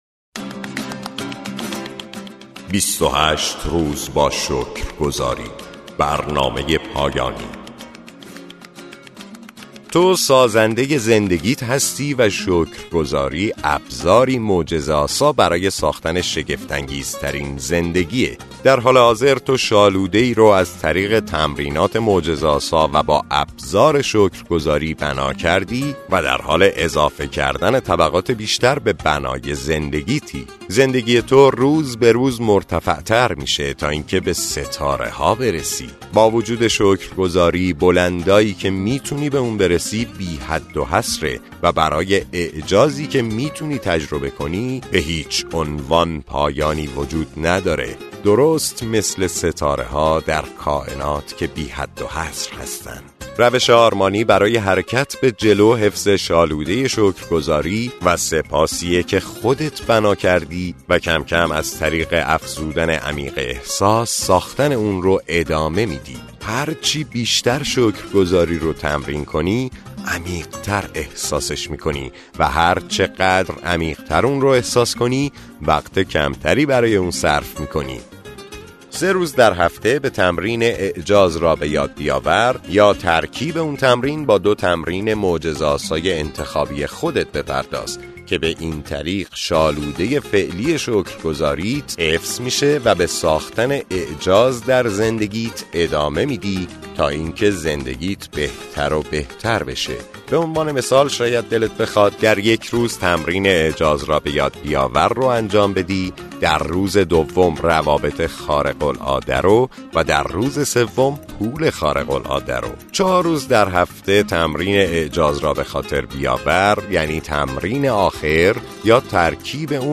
کتاب های صوتی سینرژی ، کتاب های سینرژی